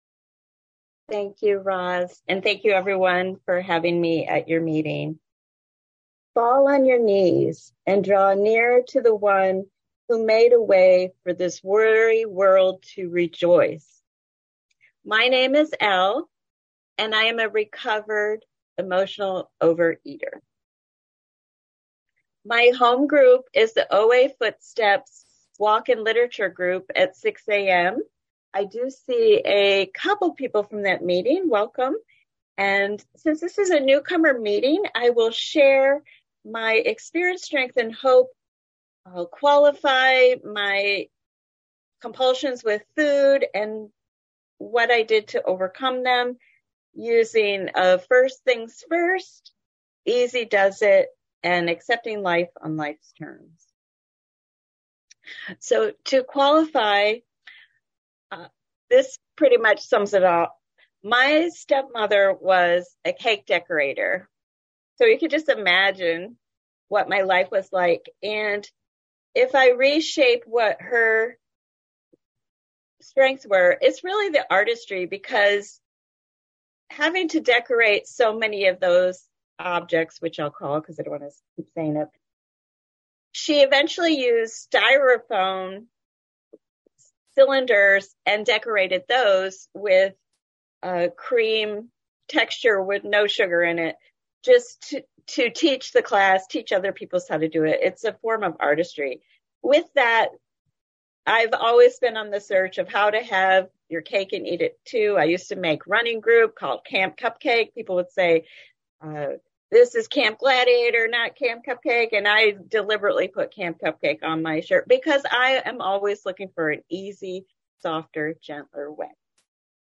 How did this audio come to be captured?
2022-12-25: Newcomer's Information Meeting 11AM EST Sundays – OA Foot Steps